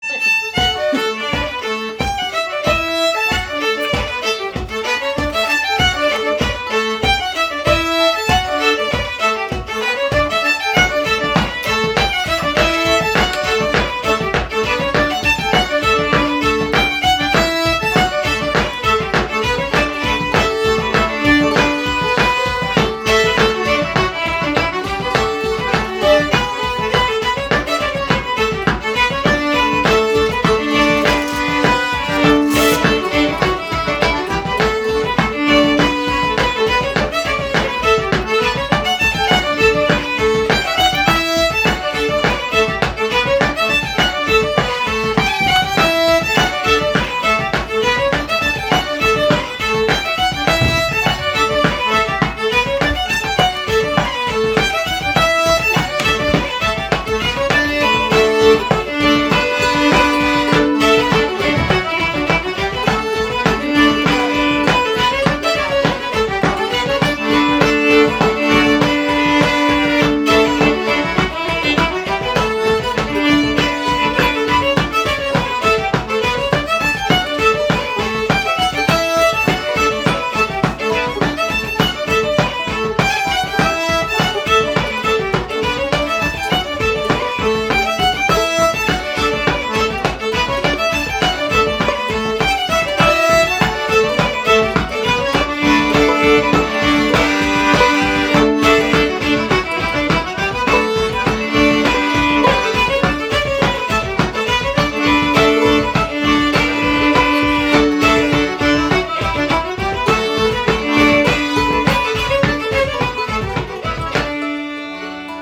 Sessions are open to all instruments and levels, but generally focus on the melody.
Composer Fortunat Malouin Type Reel Key D Recordings